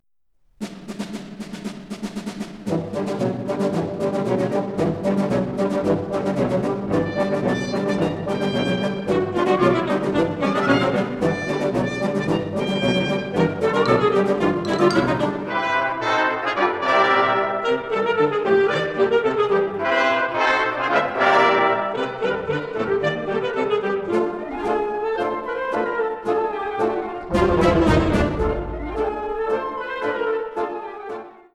für Harmonie